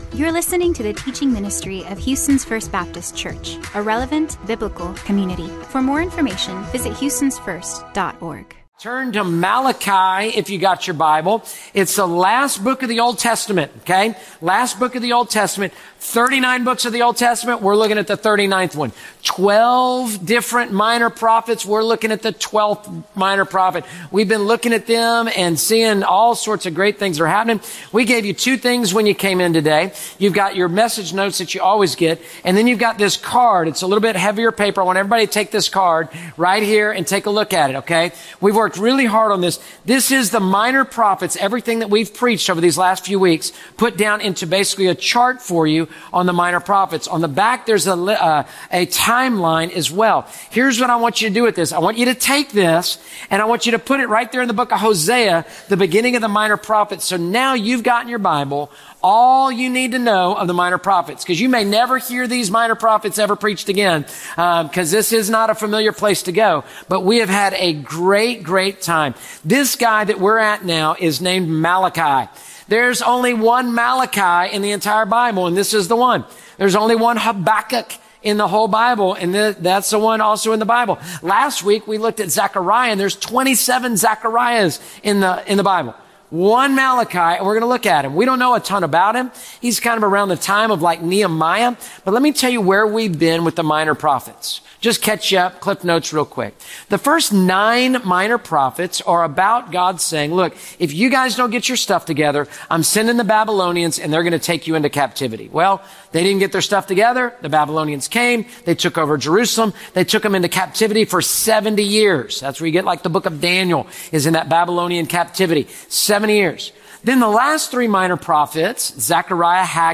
This podcast provides weekly messages